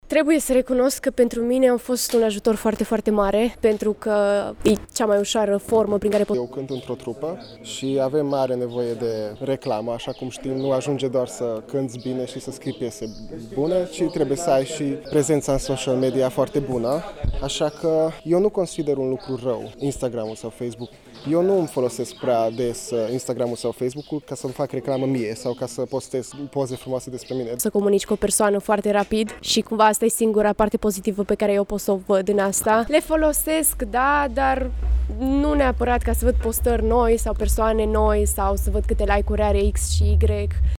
Printre problemele puse în discuție astăzi s-a numărat și felul în care like-urile ne afectează stima de sine. Pentru tinerii întâlniți la UAT Tg. platformele sociale sunt doar o modalitate de comunicare sau de promovare a proiectelor: